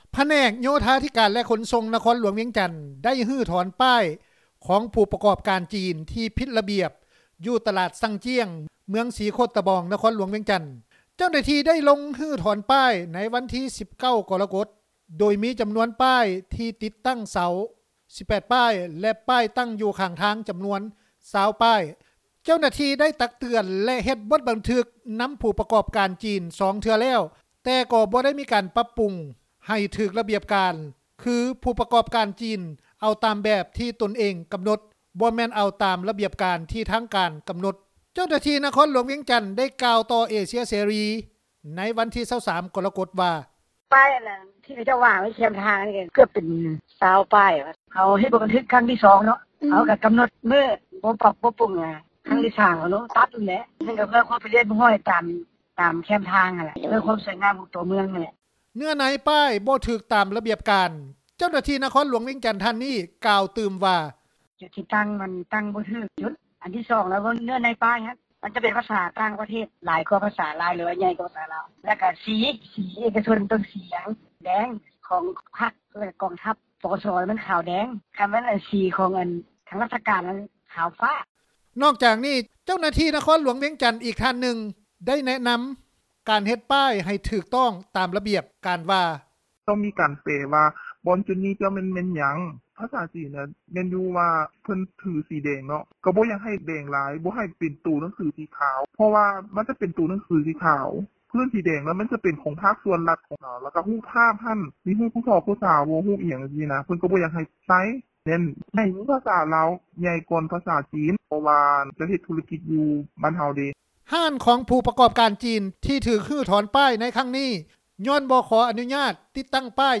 ເຈົ້າໜ້າທີ່ ນະຄອນຫລວງວຽງຈັນ ໄດ້ກ່າວຕໍ່ເອເຊັຽເສຣີ ໃນວັນທີ 23 ກໍລະກົດວ່າ:
ຜູ້ປະກອບການຈີນຄົນໜຶ່ງ ໄດ້ໃຫ້ສໍາພາດຕໍ່ເອເຊັຽເສຣີ ໂດຍໃຫ້ພະນັກງານຄົນລາວ ເປັນຜູ້ແປໃຫ້ ຊຶ່ງມີເນື້ອໃນວ່າ:
ຊາວເມືອງສີໂຄດຕະບອງ ໄດ້ກ່າວວ່າ: